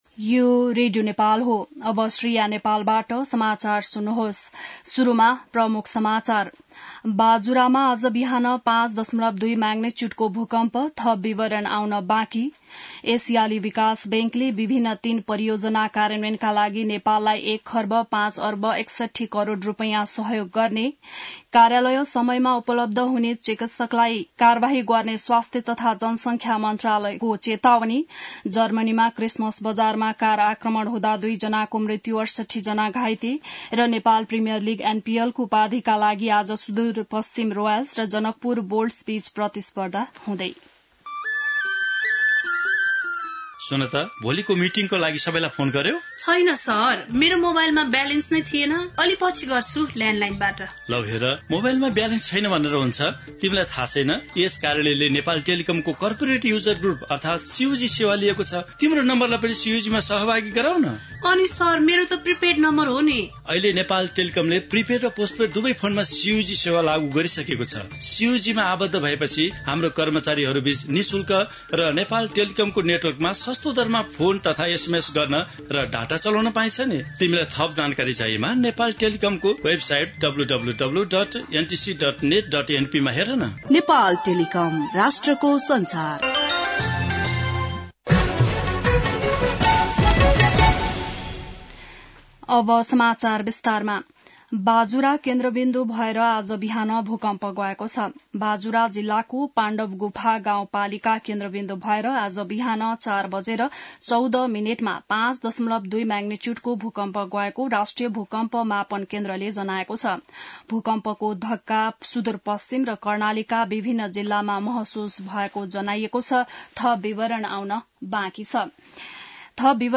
बिहान ७ बजेको नेपाली समाचार : ७ पुष , २०८१